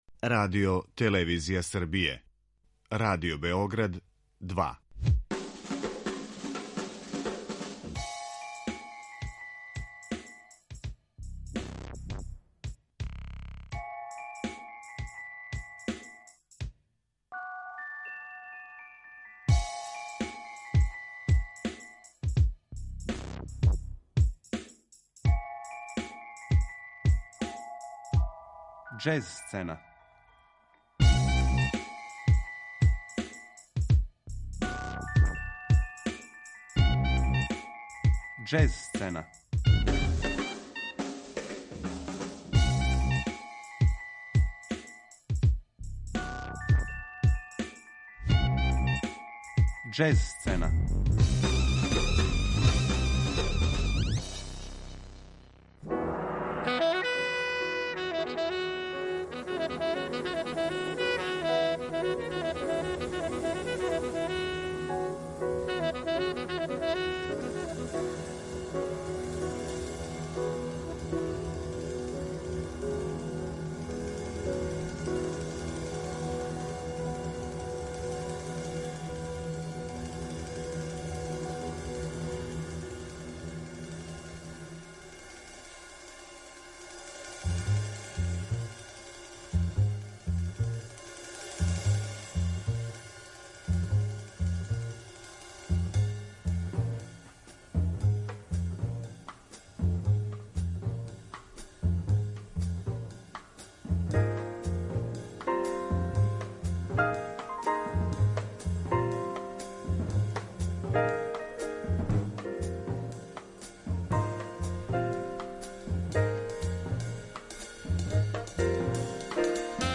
саксофонисте
трубача